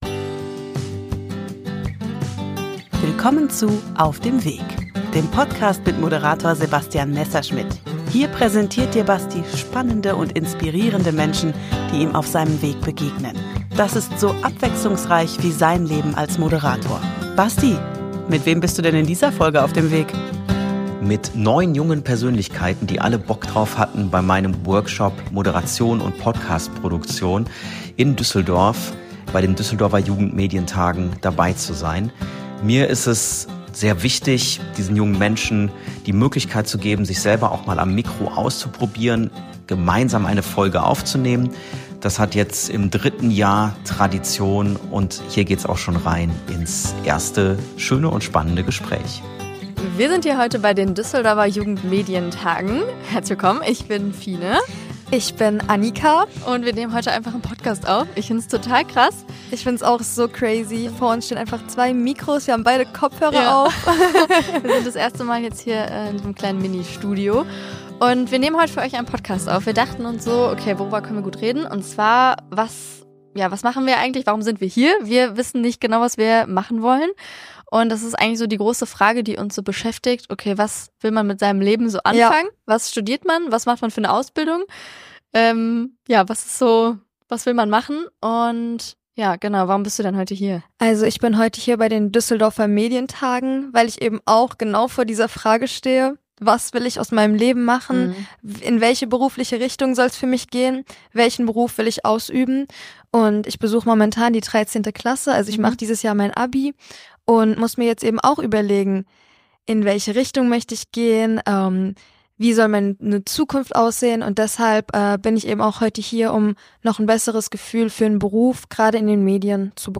Damit die Teilnehmenden (14 - 25 Jahre) direkt praktische Erfahrungen sammeln können, haben sie in Folge 135 meinen Podcast übernommen.